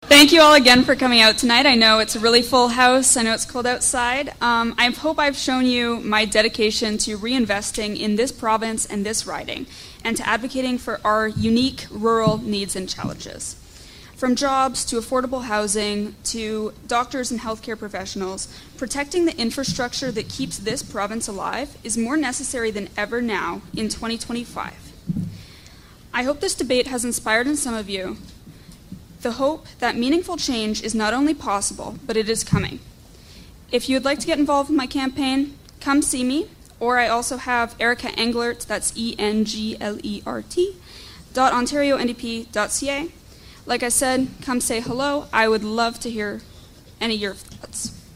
It seemed fitting that, with only one debate featuring a majority of the candidates being held, that we highlighted their closing statements from that night on the final day of the election campaign.